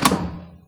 switch_2.wav